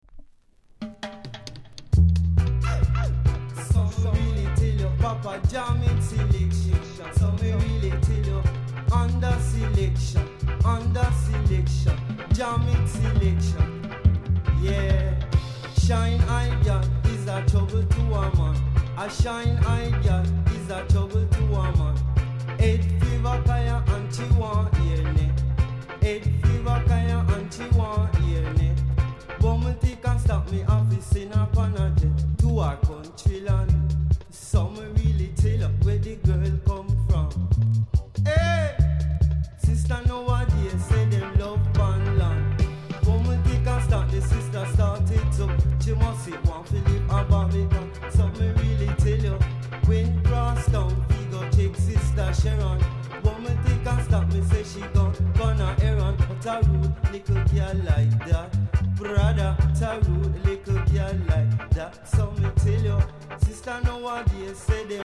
※多少小さなノイズはありますが概ね良好です。
裏はDEEJAYです。